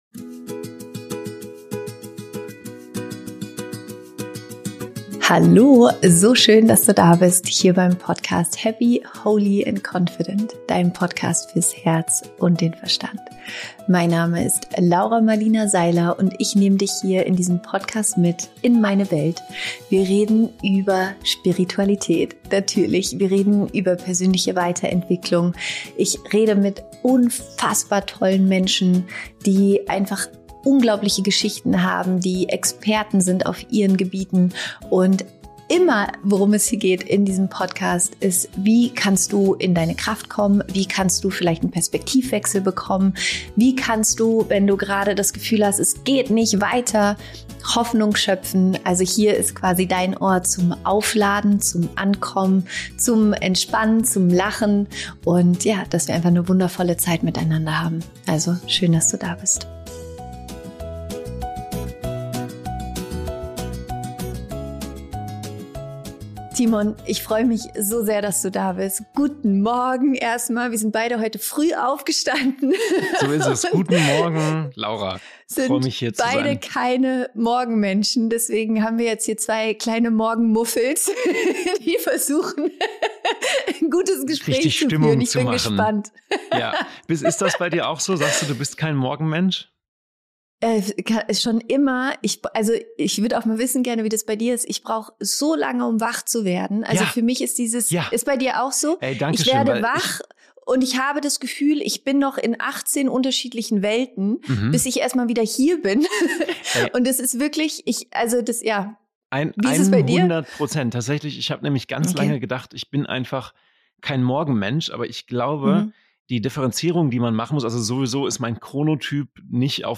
Im Gespräch mit Timon Krause erfährst du, …